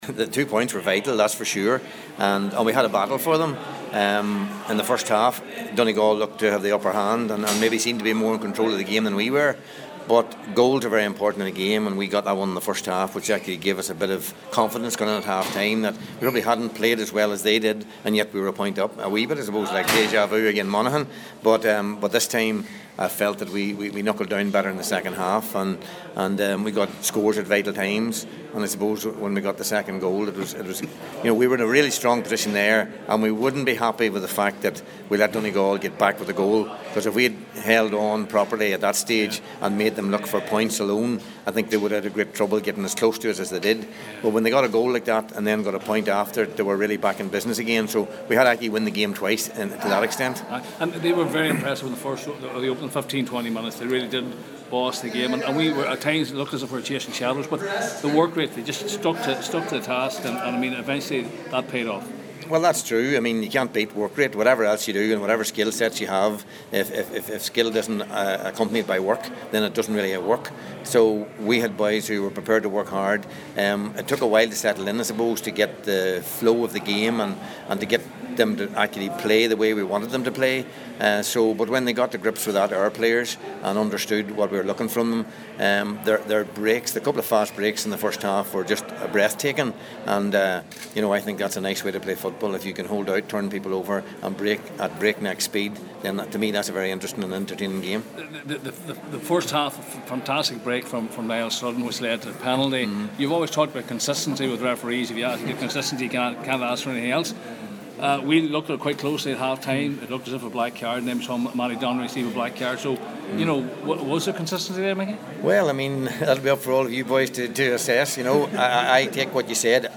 Tyrone manager Mickey Harte told the assembled media after the game that the 2 points were vital…